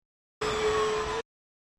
These sounds are primarily industrial / noise type sounds.
The following is a series of very short samples.
sound 7 1 sec. mono 20k